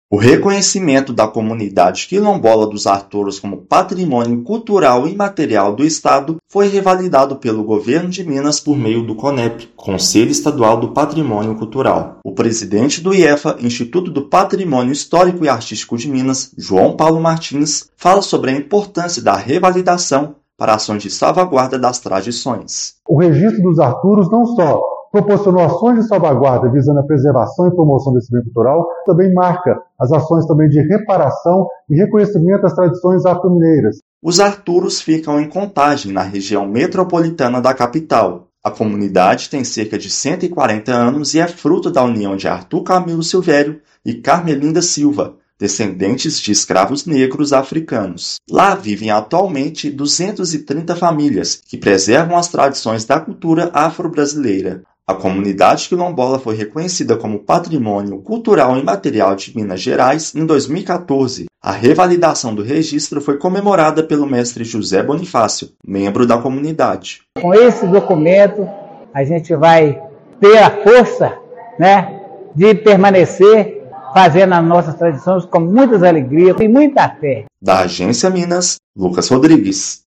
[RÁDIO] Estado revalida reconhecimento da Comunidade Quilombola dos Arturos como Patrimônio Imaterial de Minas Gerais
Decisão unânime reforça a importância da memória e das tradições da comunidade centenária de Contagem. Ouça matéria de rádio.